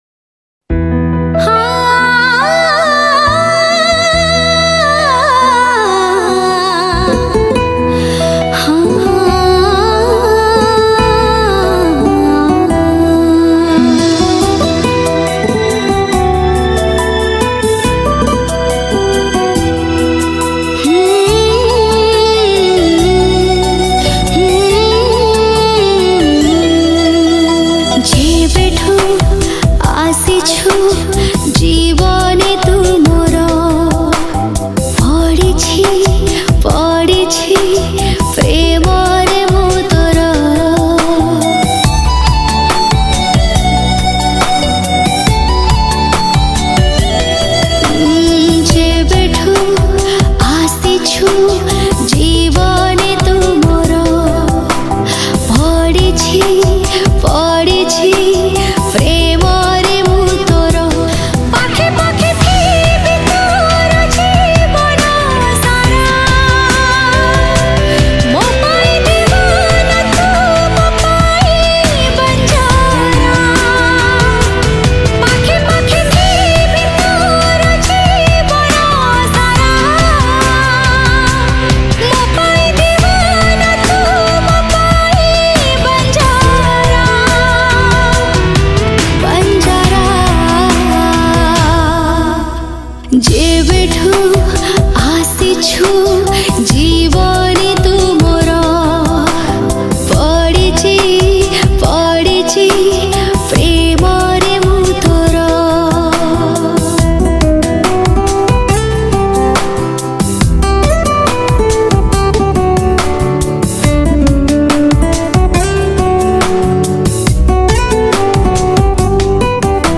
Odia Songs